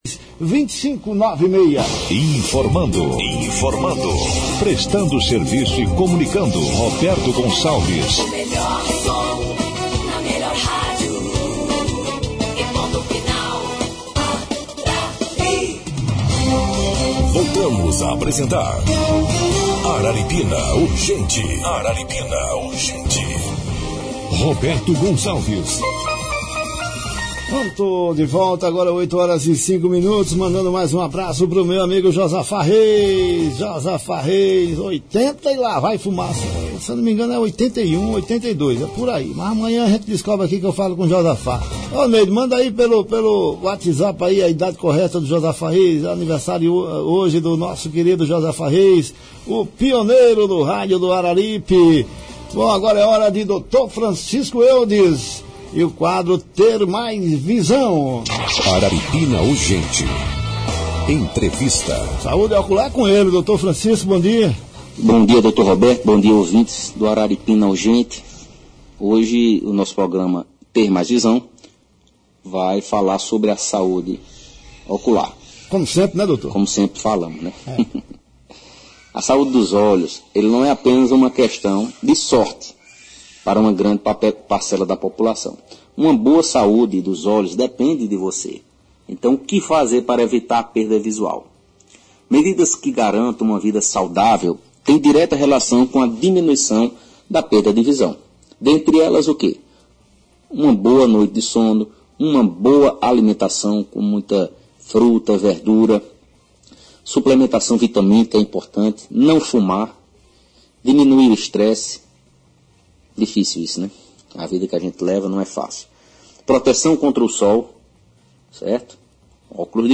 O especialista explicou na Arari FM o que fazer para evitar a perda visual.; ouça